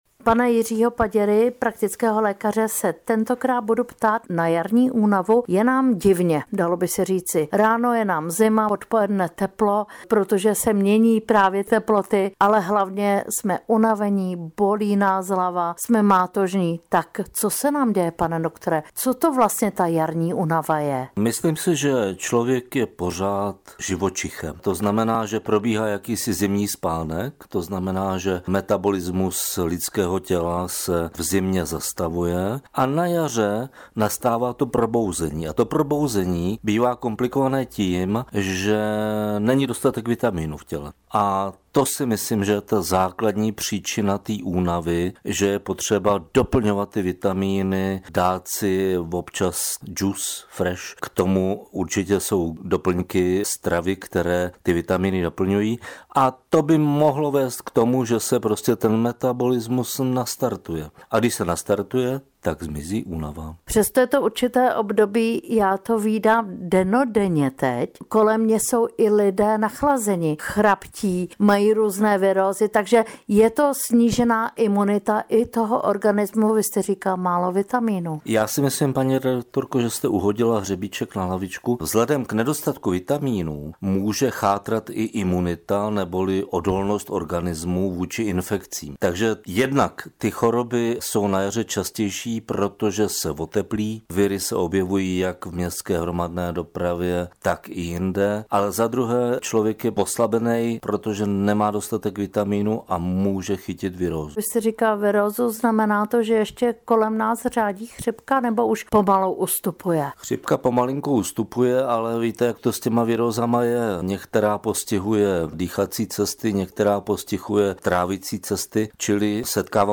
Audio rozhovor